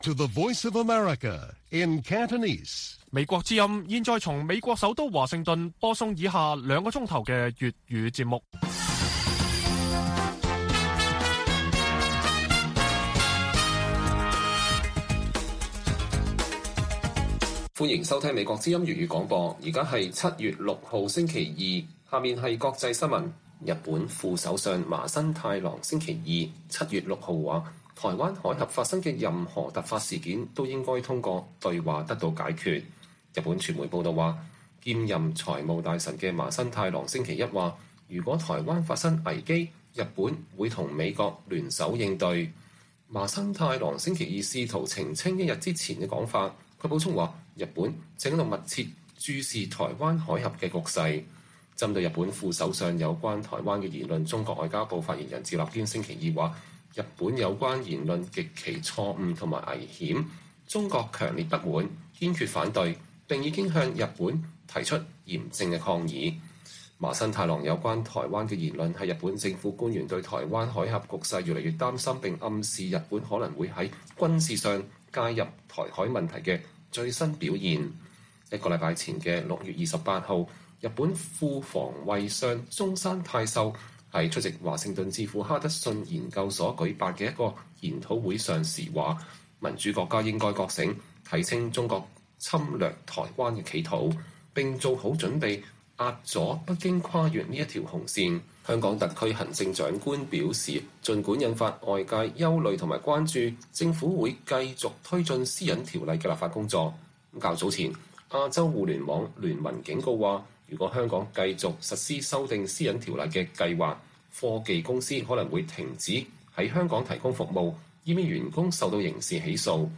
粵語新聞 晚上9-10點：特首續推私隱條例 谷歌臉書可能撤離香港